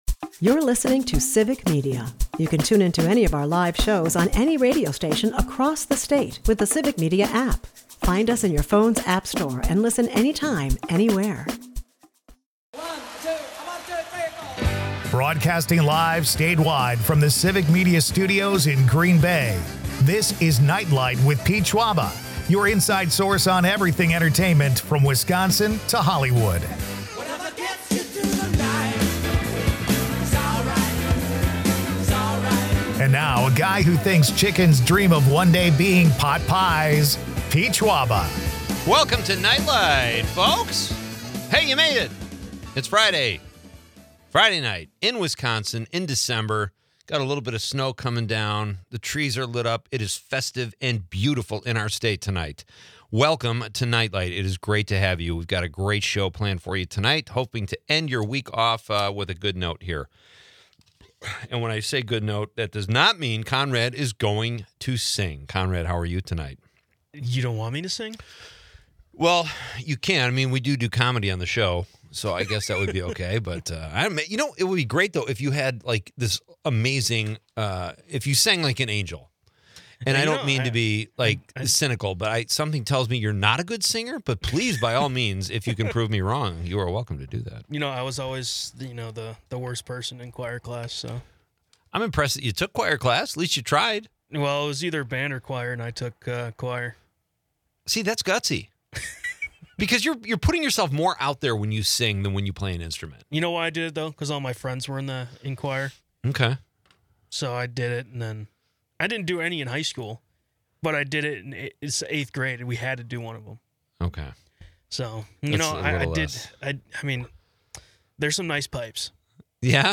Listeners share their favorite buddy movies, adding to the lively conversation. With a packed guest list, the show buzzes with holiday cheer and local flavor.